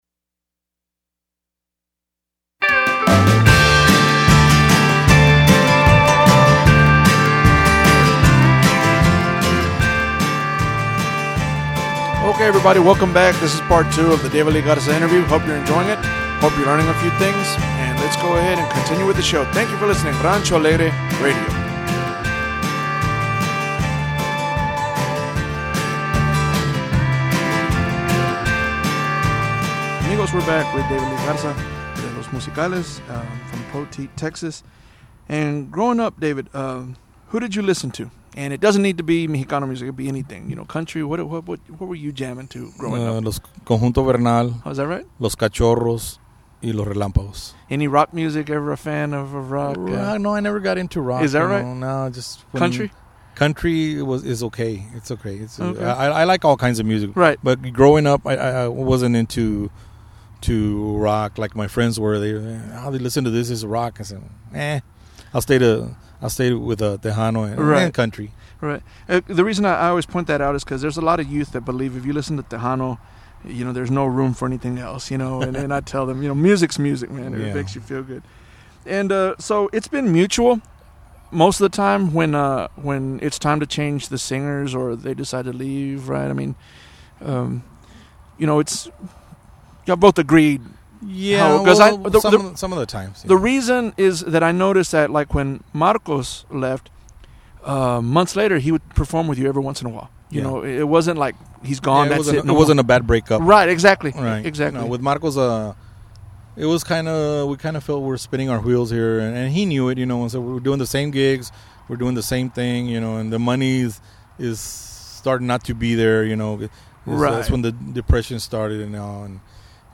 Rancho Alegre Interview - David Lee Garza